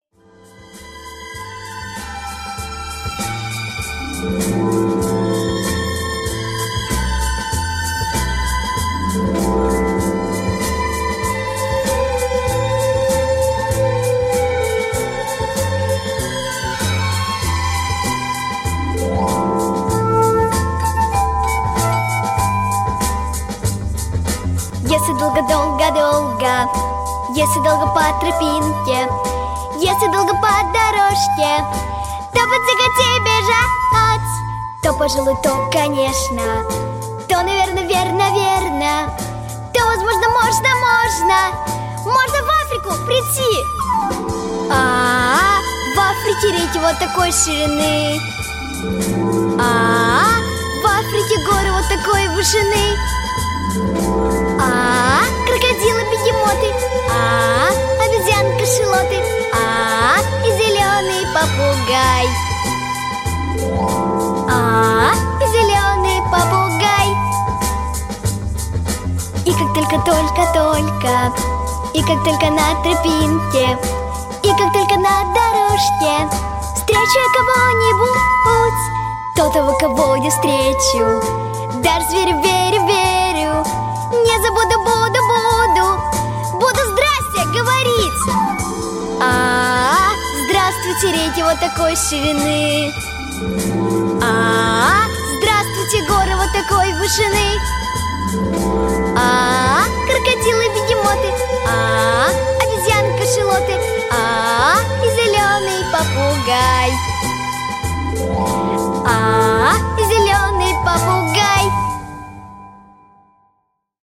• Категория: Детские песни
советские детские песни